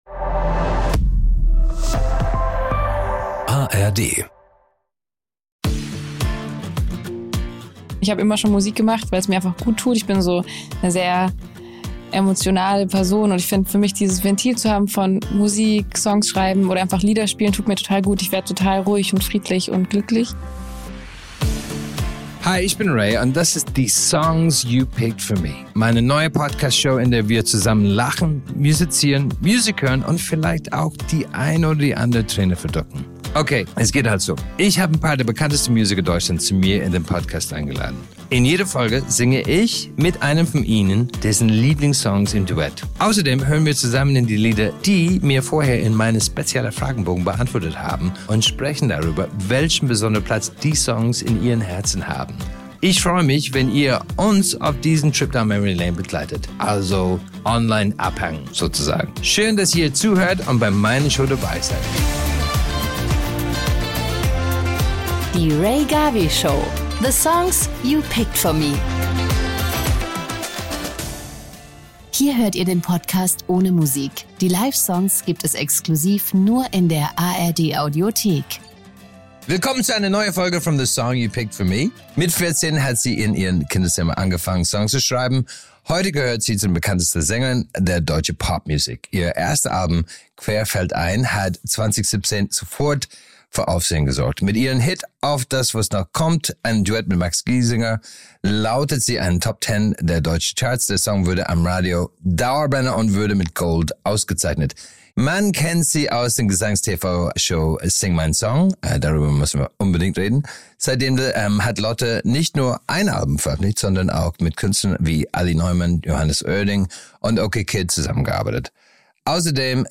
Natürlich gibt es auch wieder zwei exklusive Performances, die ihr nur in der ARD Audiothek hören könnt.